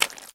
STEPS Swamp, Walk 26.wav